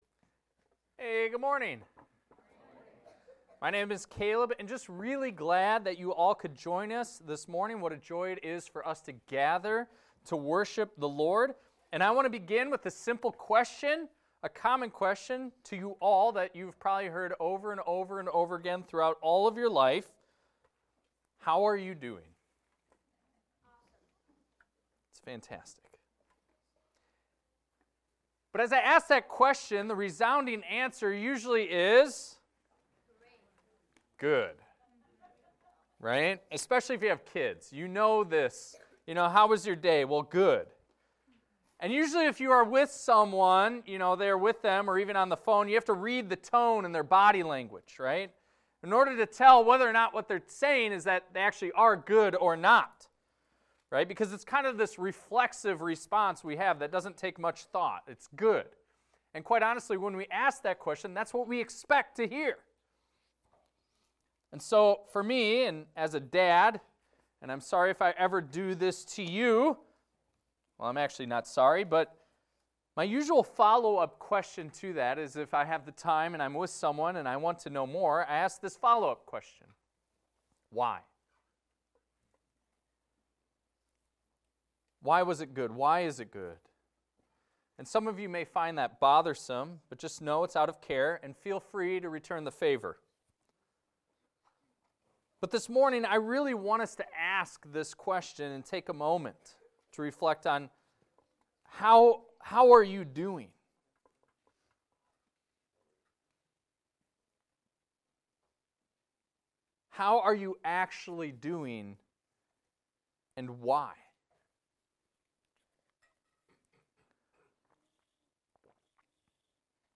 This is a recording of a sermon titled, "Know God And Be Known."